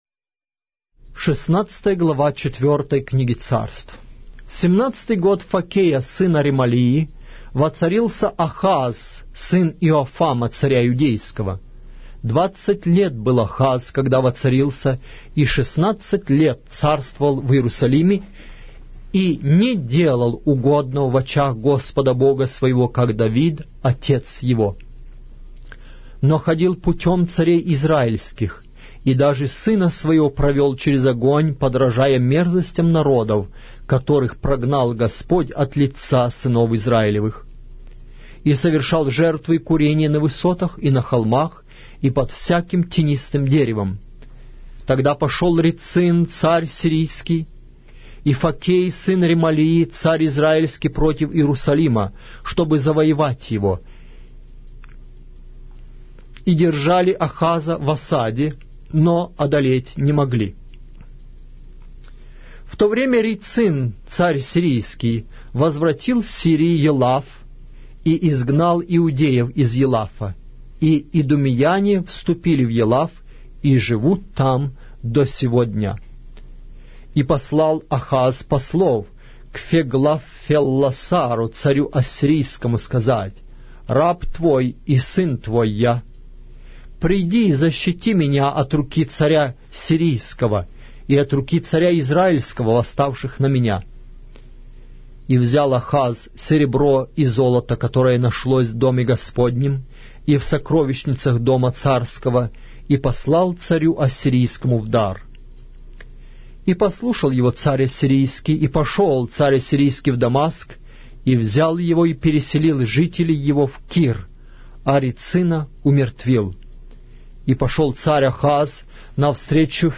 Аудиокнига: 4-я Книга Царств